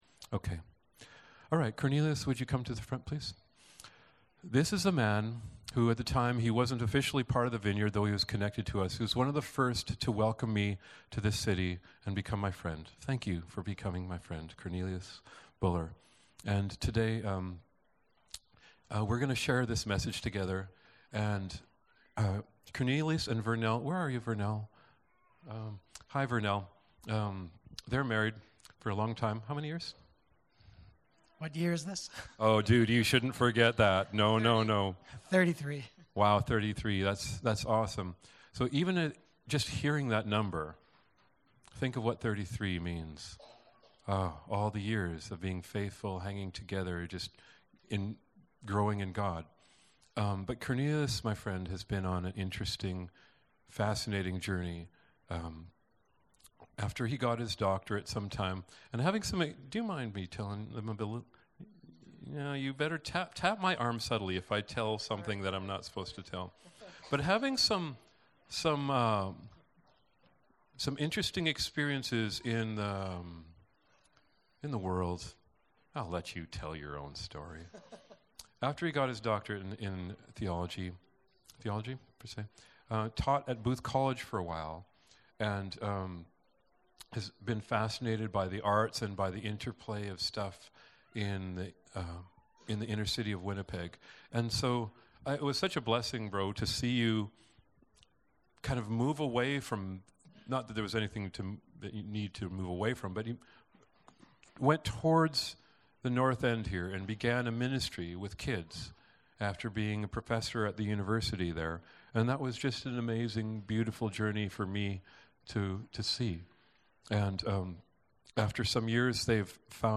Bible Text: Deuteronomy 26:11 | Preacher